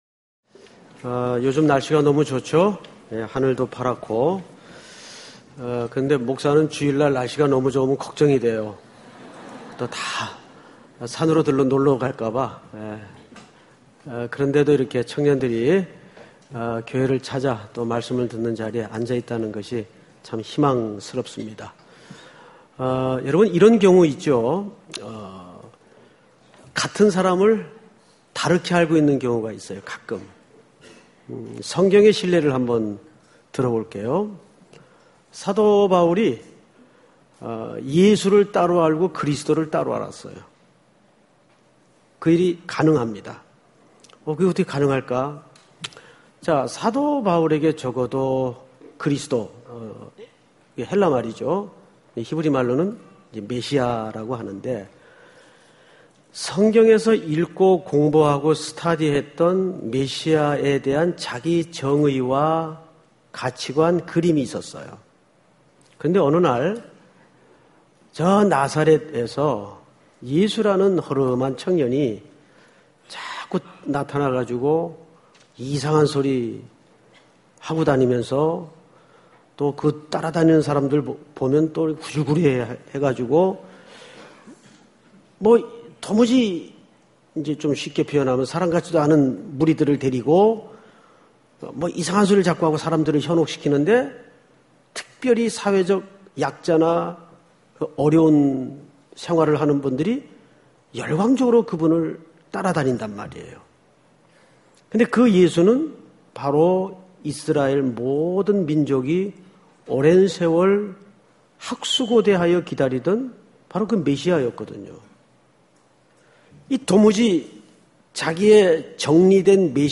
주일저녁예배